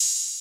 {OpenHat} COORDINATE 2.wav